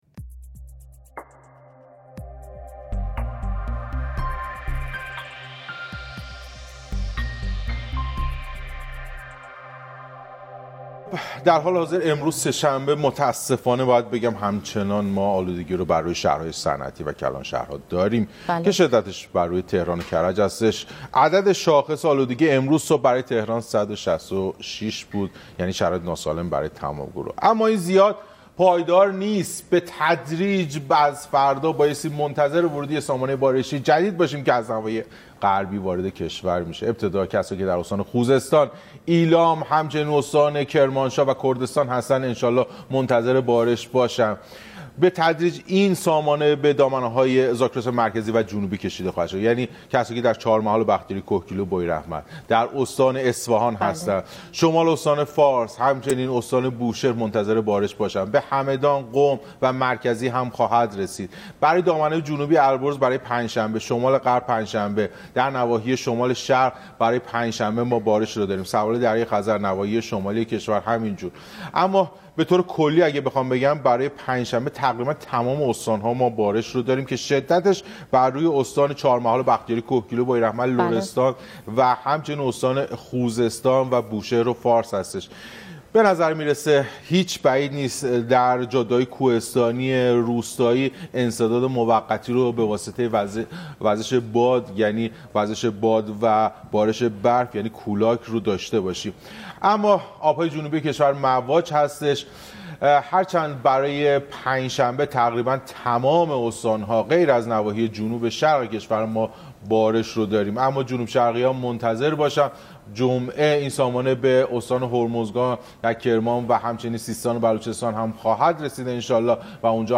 رادیو خبری ایمنا/